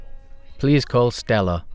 VoiceBank+DEMAND_test_sample_male_1.wav